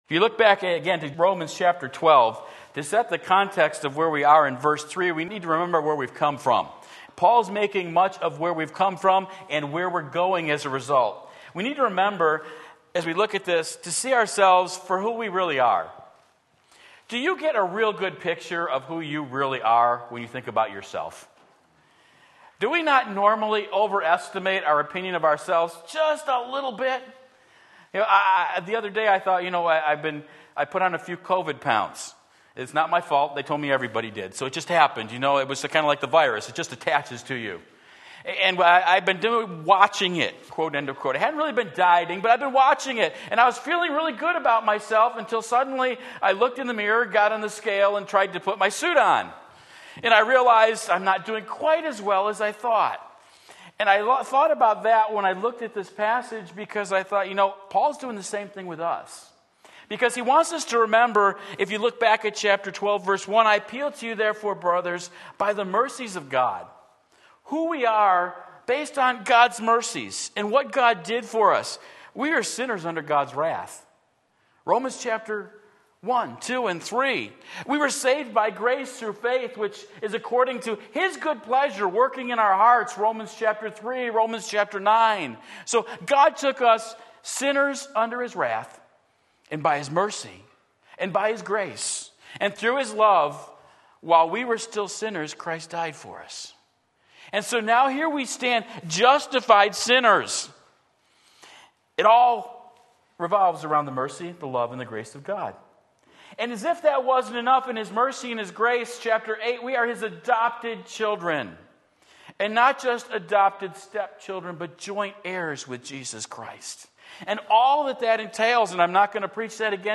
Sermon Link
Seeing Ourselves for Who We Are Romans 12:3 Sunday Morning Service